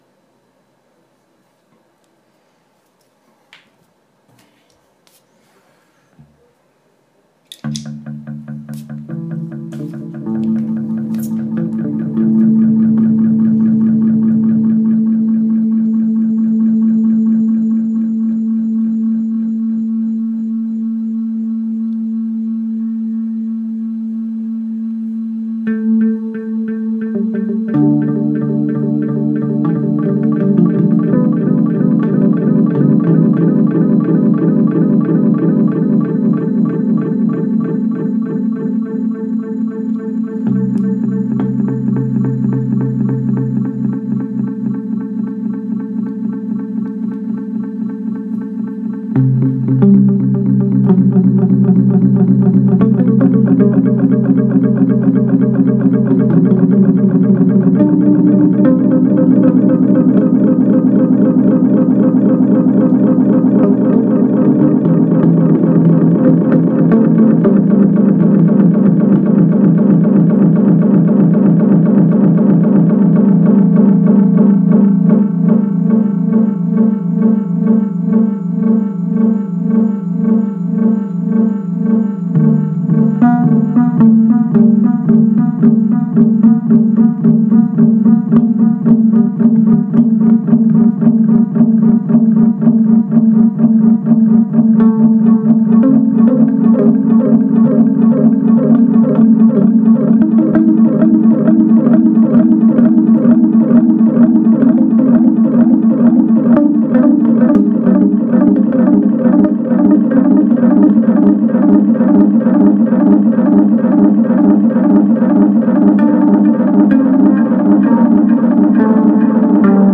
Second Reading
Recorded 14th September, 2016 in Madrid.